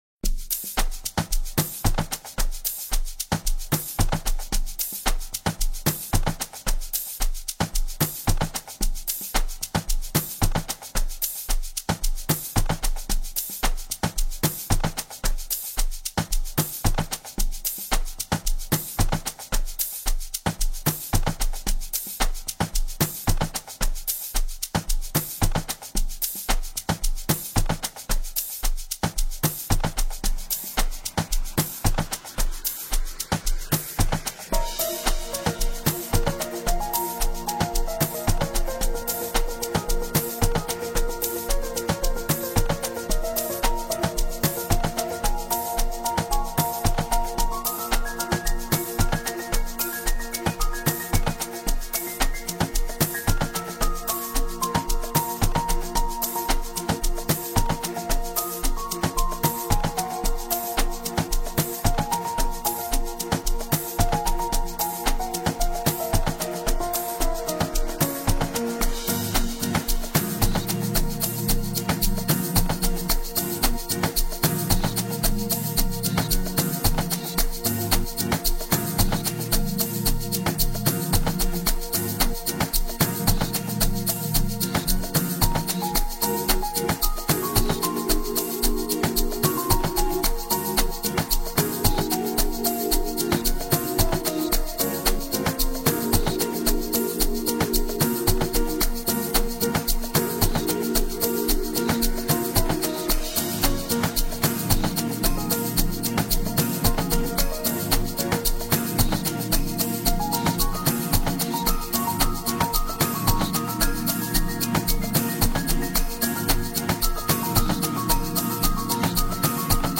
With his super-clean sound design